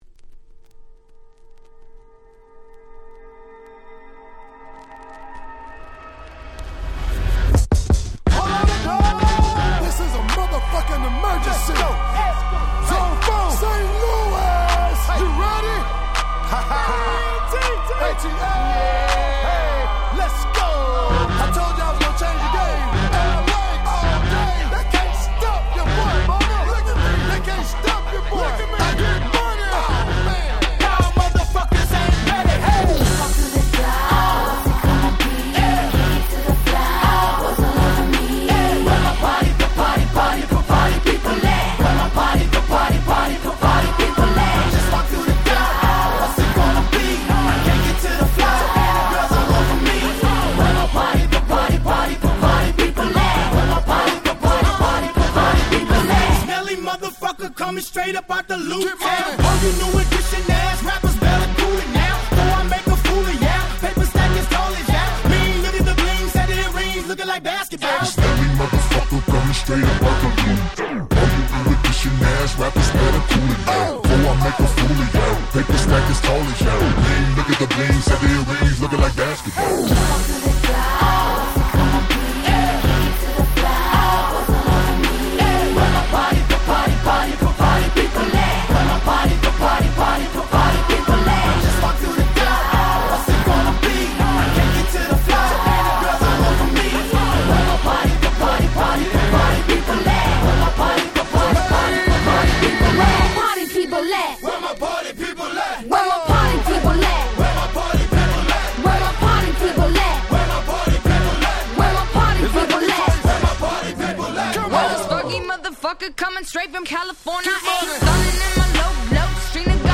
08' Smash Hit Hip Hop !!